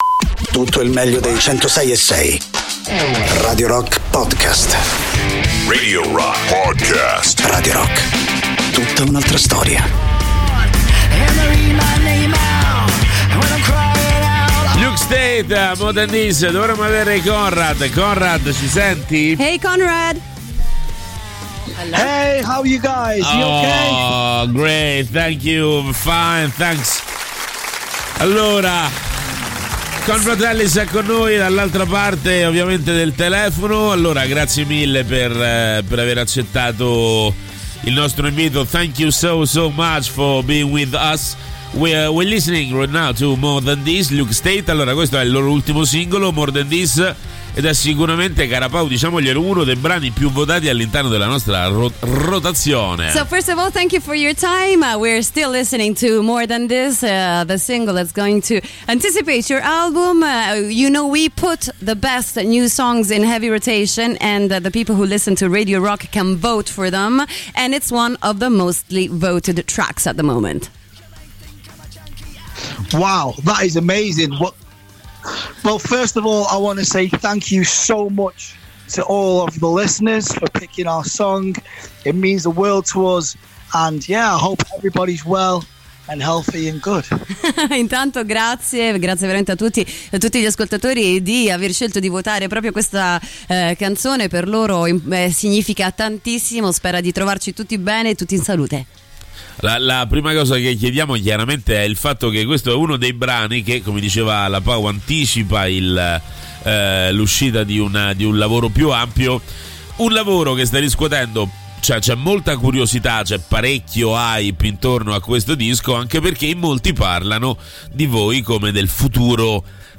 Interviste: The Luka State (08-12-22)